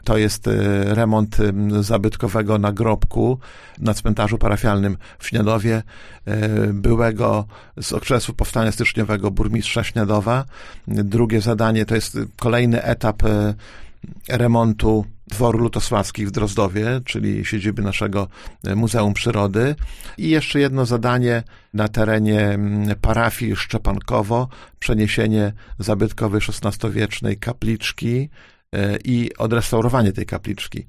O przebiegu tego procesu mówił w audycji Gość Dnia Radia Nadzieja, starosta powiatu, Lech Marek Szabłowski: